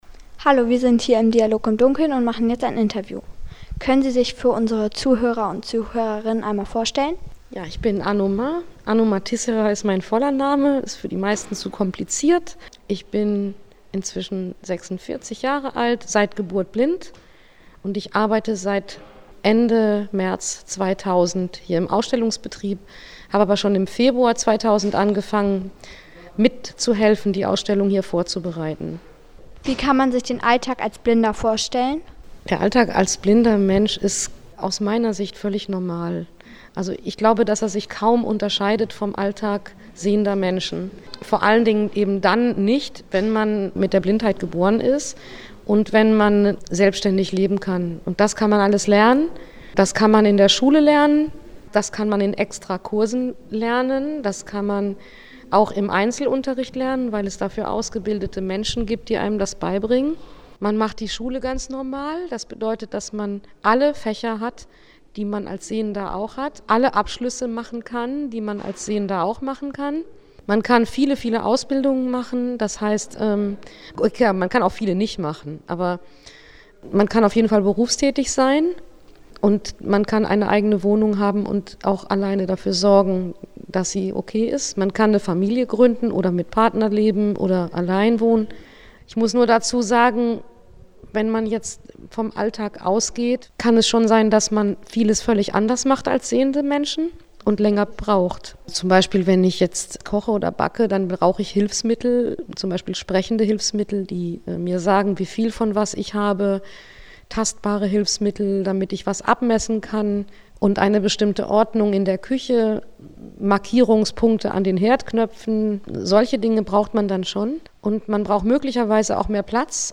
Dabei hatten wir unser Mikrofon mit und es war gar nicht so einfach, damit im Dunkeln Aufnahmen zu machen!